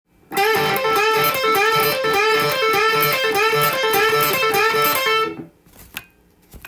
エレキギターで弾ける【ランディーローズフレーズ集】tab譜
フレーズ集は、全てDm　keyで使用できるものになっています。
①のフレーズは、Dｍペンタトニックスケールの王道パターンを
６連符で繰り返しています。